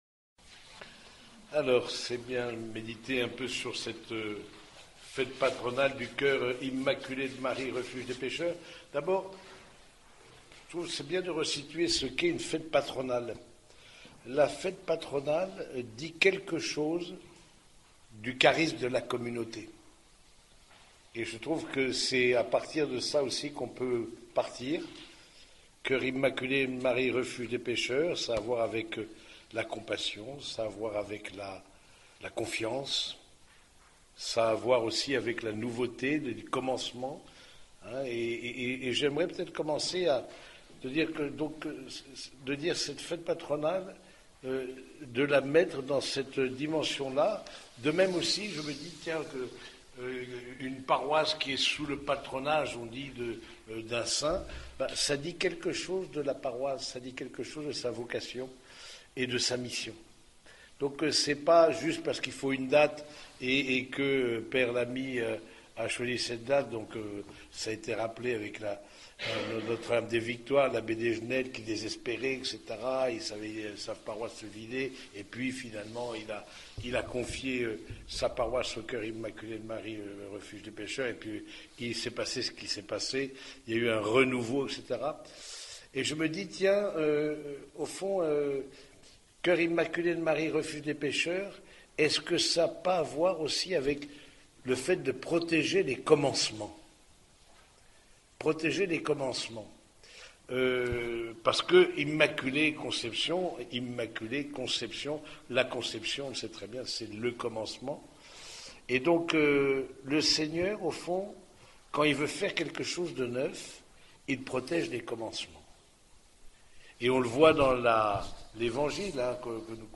Fête patronale (18 janvier 2026)